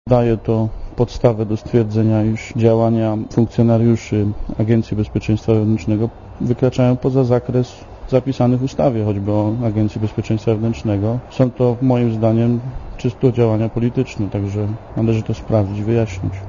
Te informacje prezes NIK przedstawił nam podczas posiedzenia komisji do spraw służb specjalnych - mówi Andrzej Grzesik z Samoobrony.